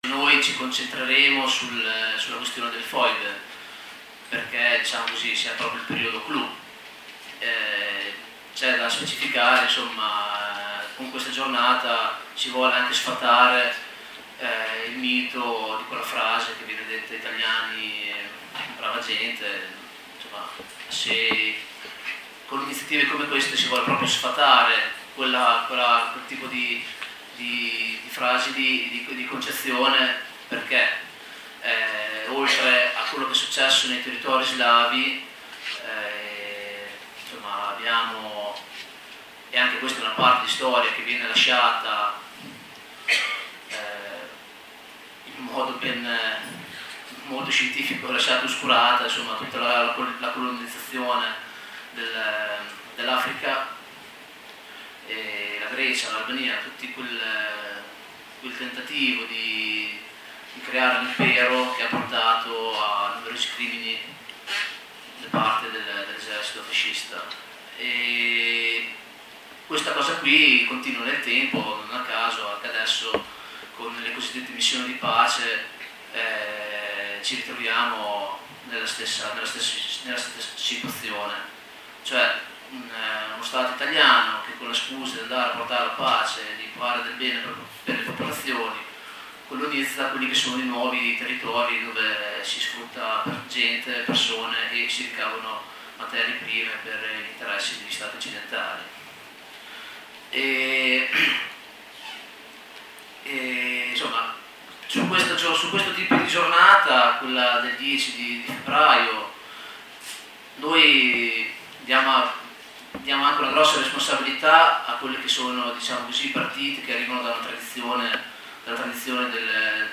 Questo il resoconto audio della serata: